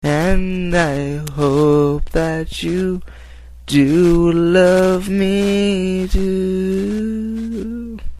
Tags: Holiday Valentines Love Sweet songs Love songs